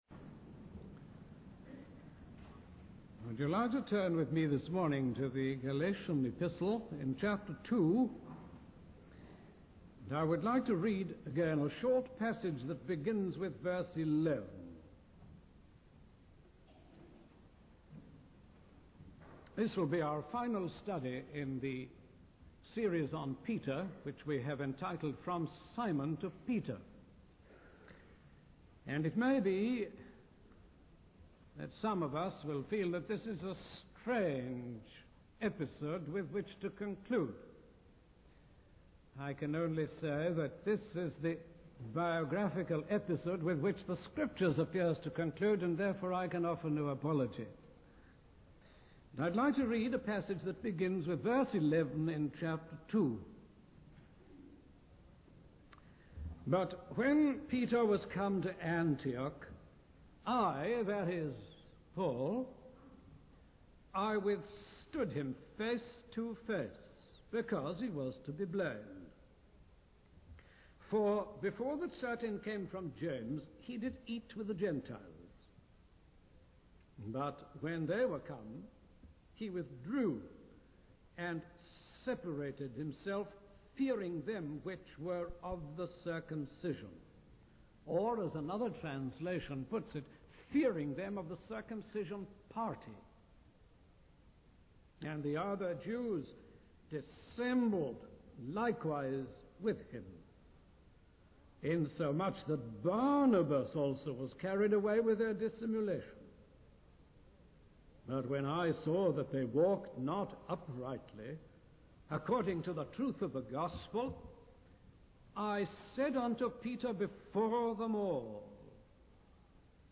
In this sermon, the speaker discusses Peter's attitude towards valid criticism from Paul. The sermon emphasizes the importance of honesty as a key to spiritual reality and maturity.